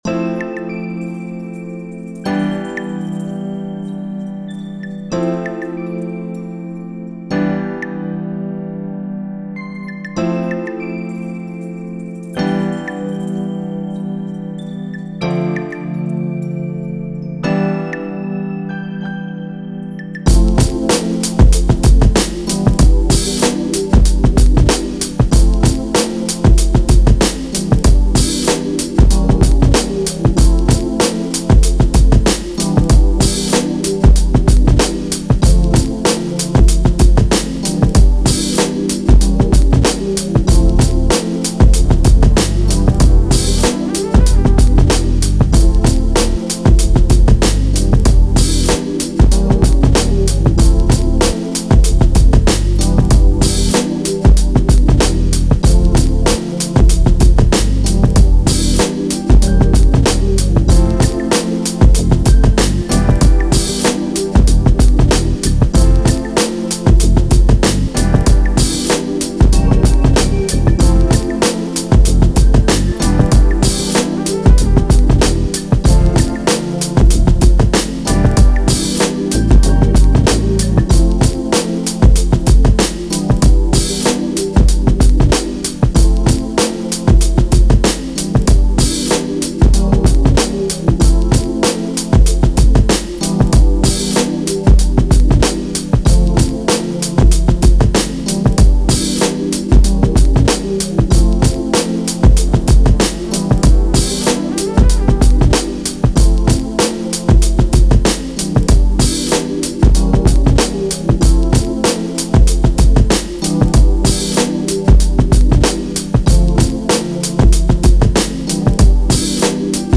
90s, Hip Hop
A minor